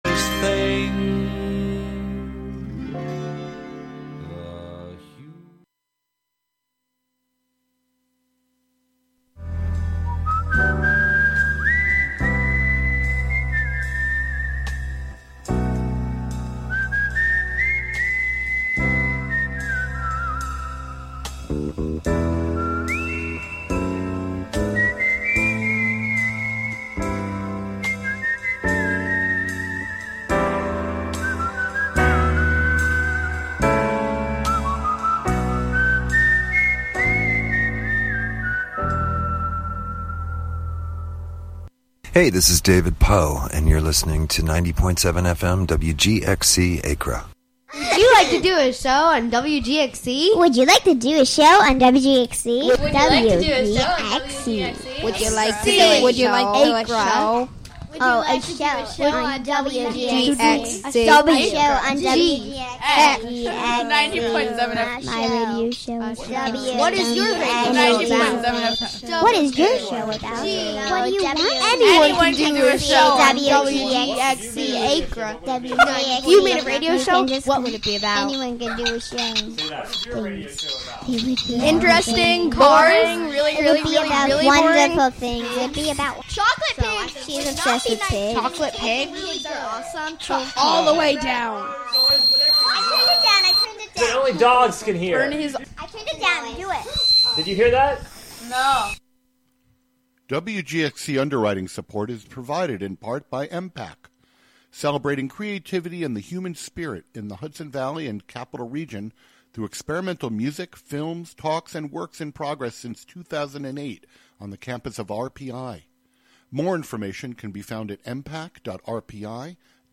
Unwind after work with an hour of ultra smooth R&B